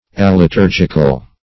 Search Result for " aliturgical" : The Collaborative International Dictionary of English v.0.48: Aliturgical \Al`i*tur"gic*al\, a. [Pref. a- + liturgical.]